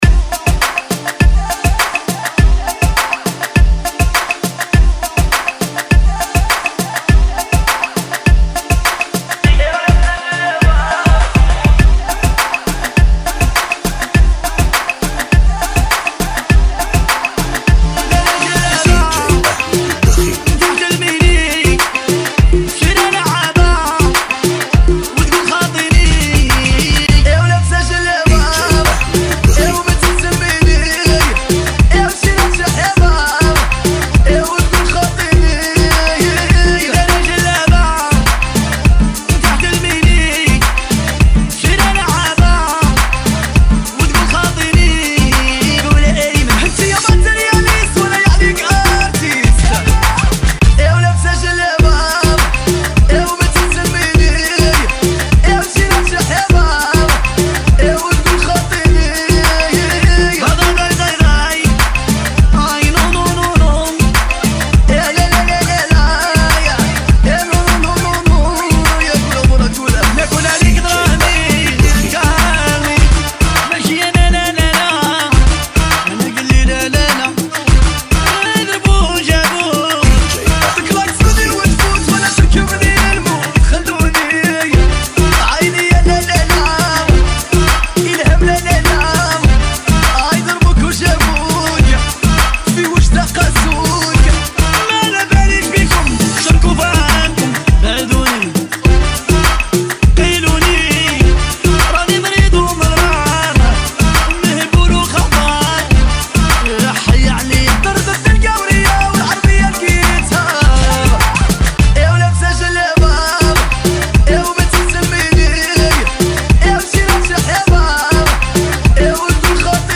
Funky Remix